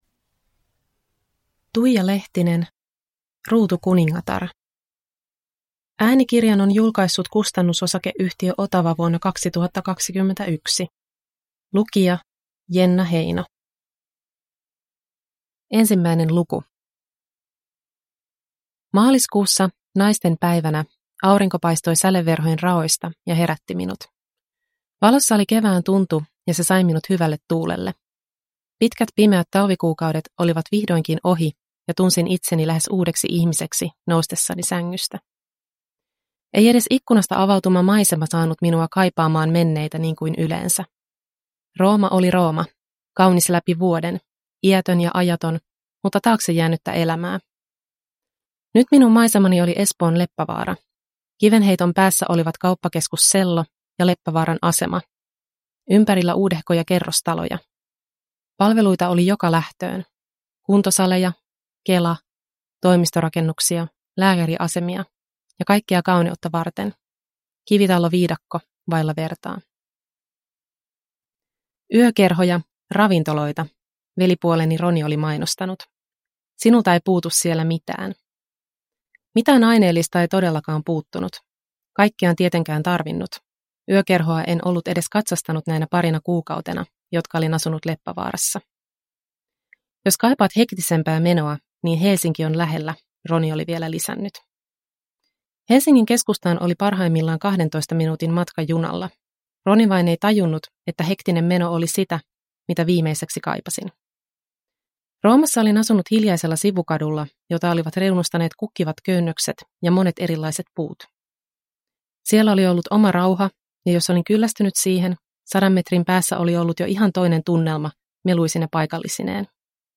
Ruutukuningatar – Ljudbok – Laddas ner